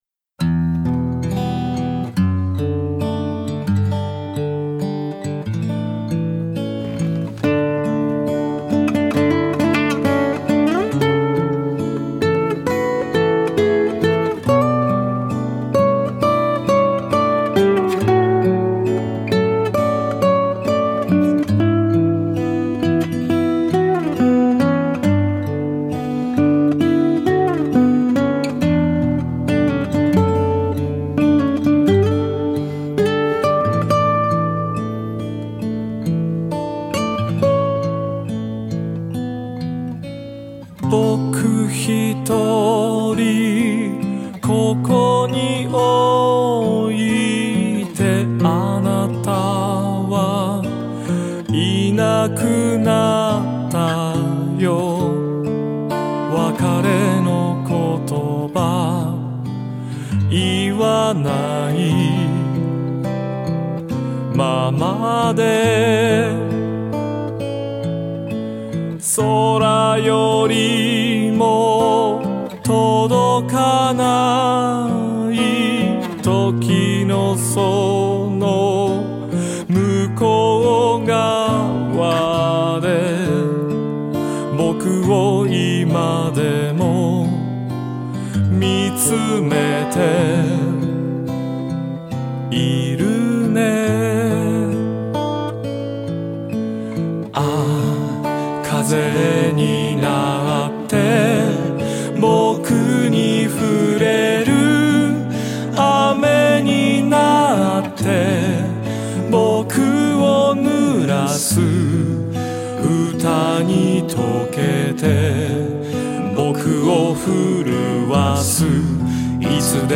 癒しオーラに満ちた良い曲だよね。